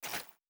Grenade Sound FX
Throw4.wav